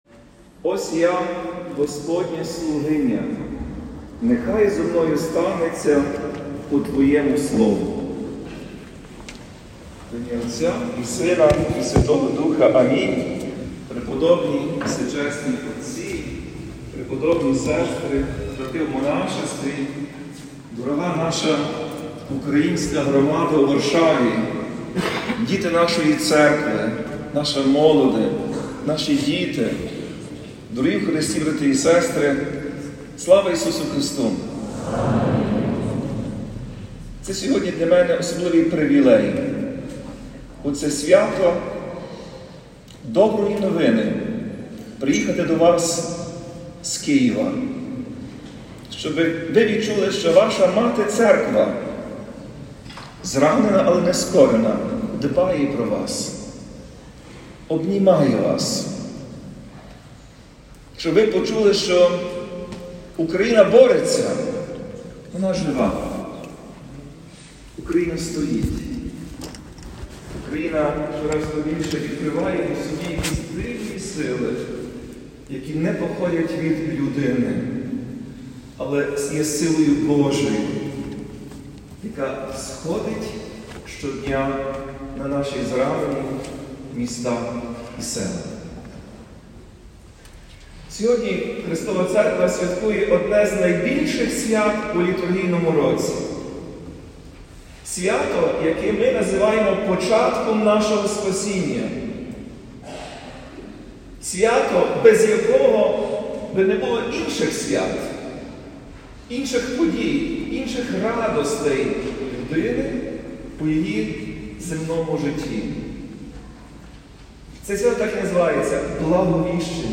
На цьому наголосив Отець і Глава Української Греко-Католицької Церкви під час проповіді на свято Благовіщення.
25 березня, у день Благовіщення Пресвятої Богородиці, Блаженніший Святослав очолив Божественну Літургію у храмі Успіння Пресвятої Богородиці у Варшаві.